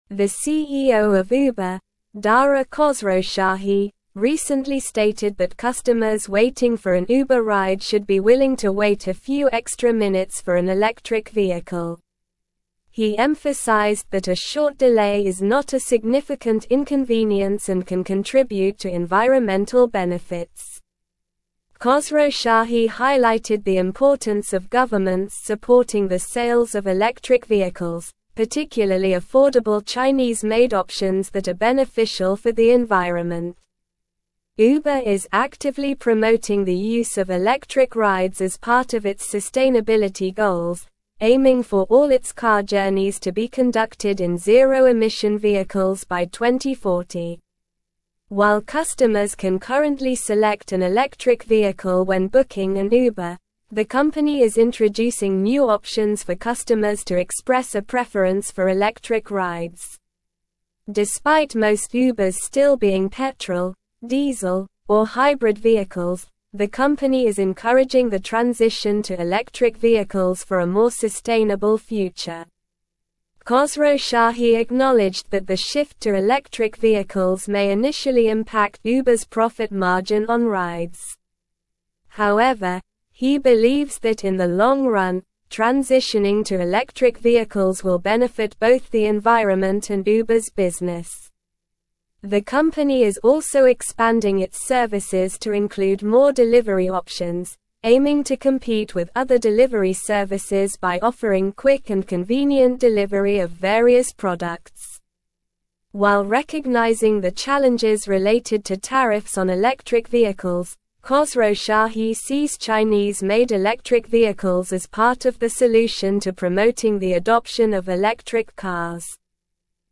Slow
English-Newsroom-Advanced-SLOW-Reading-Uber-CEO-Urges-Patience-for-Electric-Rides-Transition.mp3